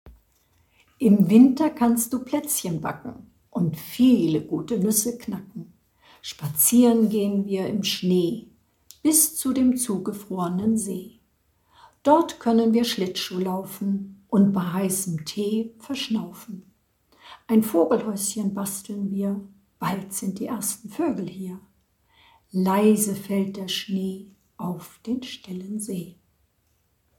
eine kleine Weihnachtsgeschichte